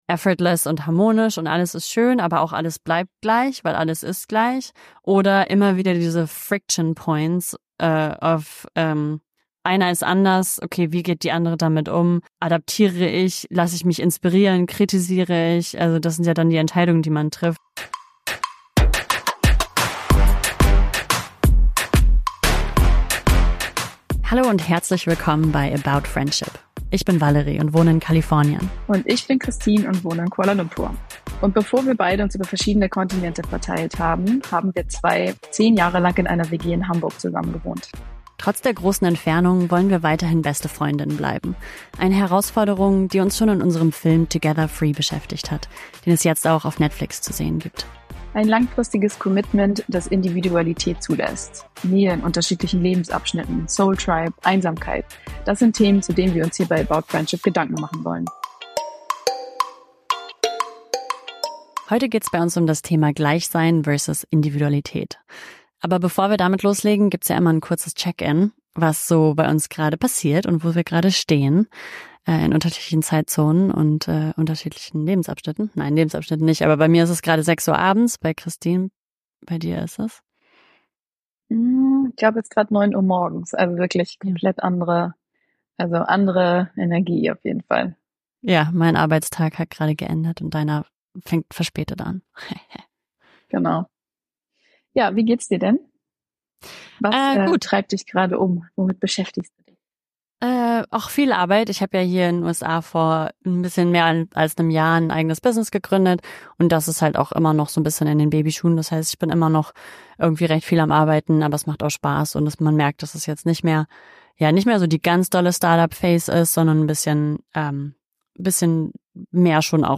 Warum gleich sein glücklich macht Was es bedeutet, seine Freund:innen zu ‘hosten’ Warum Anderssein ein Closeness-Killer ist Strategien, um sich in die gleiche Energie zu bringen PS: Sorry für die kleinen Soundprobleme.